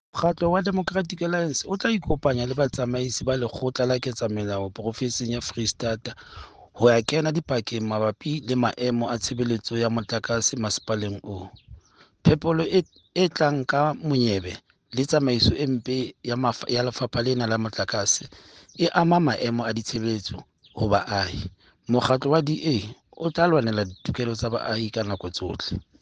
Sesotho soundbites by Cllr Stone Makhema and